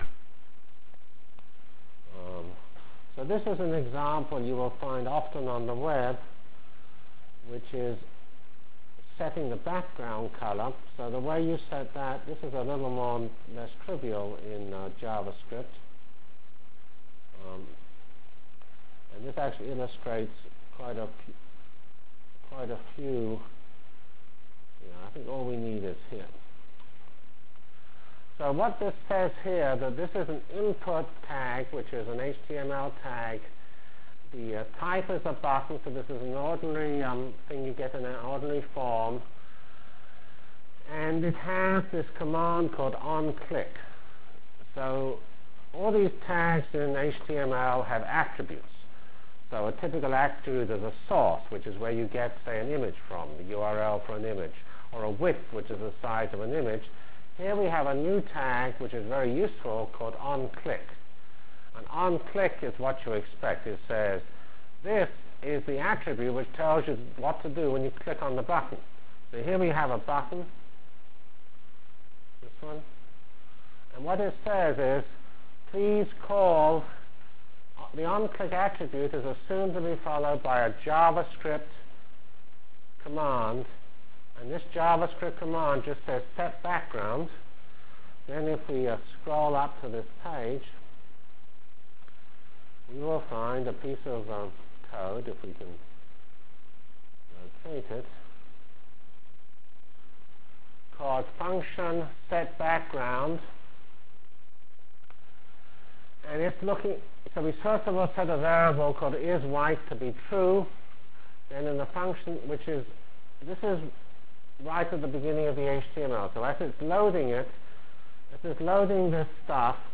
Full HTML for GLOBAL Feb 12 Delivered Lecture for Course CPS616 -- Basic JavaScript Functionalities and Examples